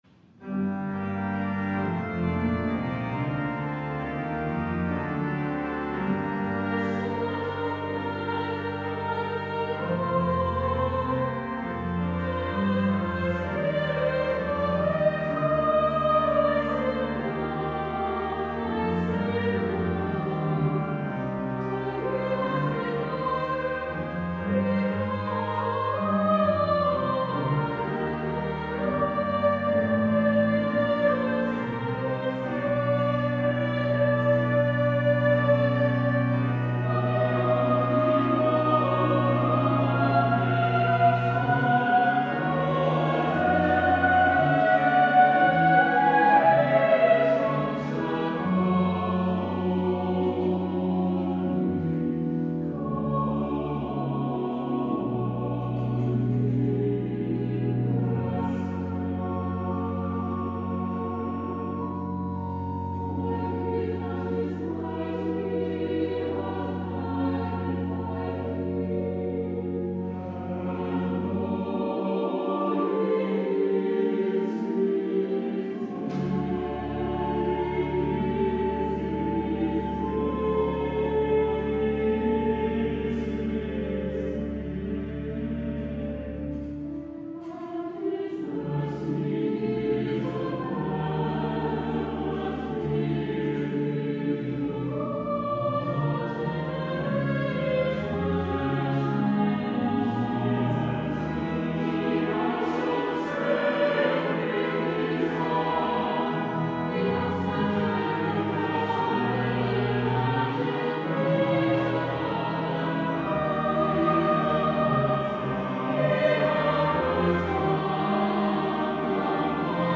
Magnificat and Nunc Dimittis (SATB and Organ) (1080p).mp3